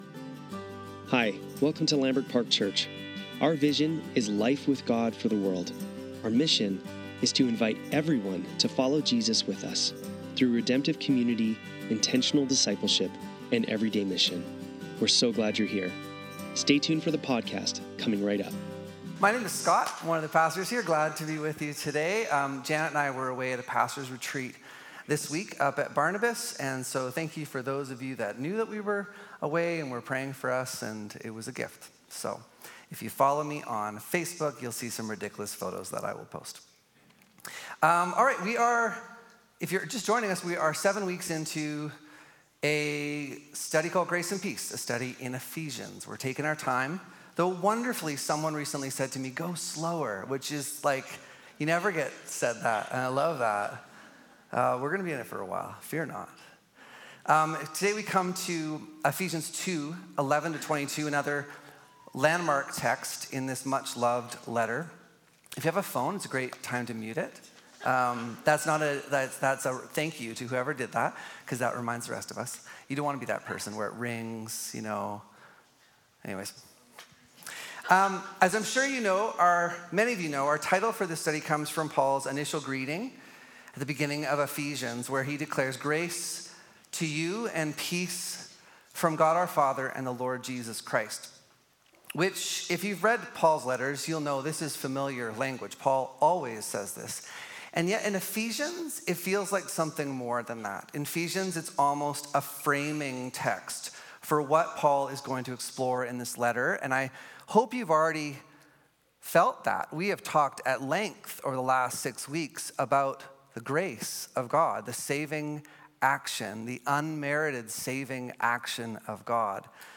Sunday Service - November 17, 2024